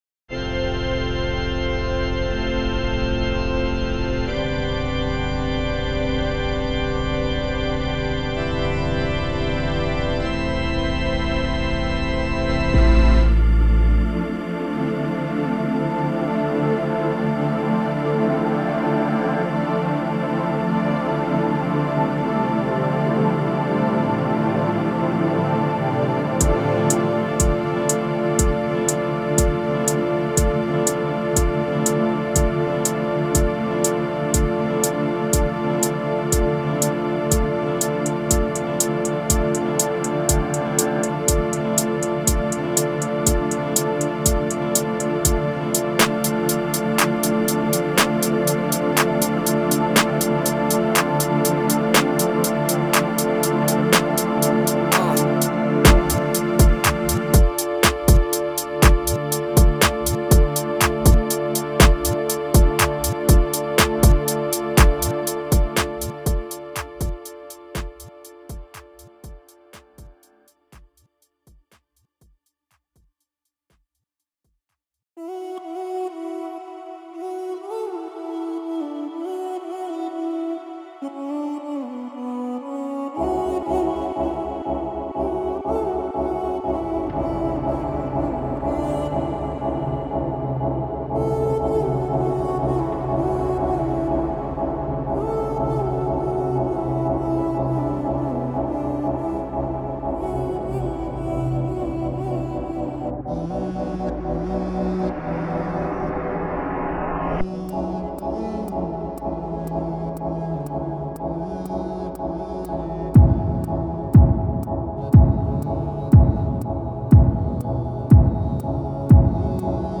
spacious, evocative scenes